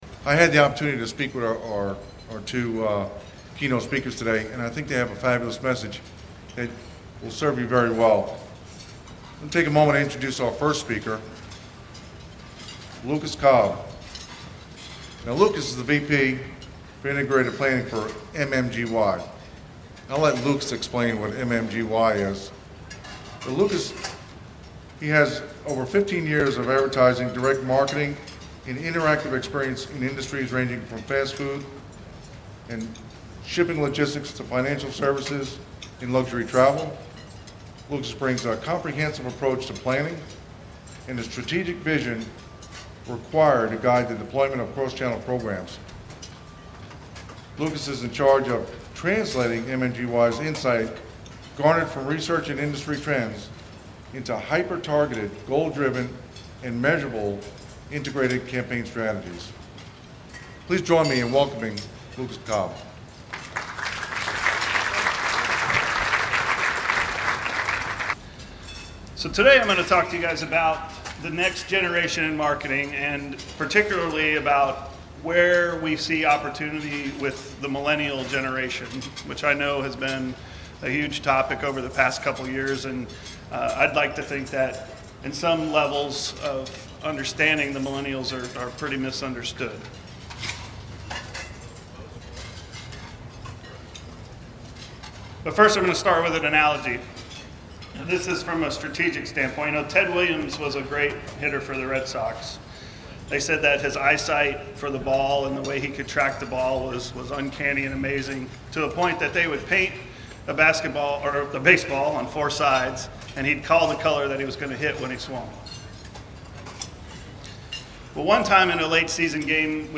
Radio StPete Live Coverage
speaking today at Beaches Chamber Luncheon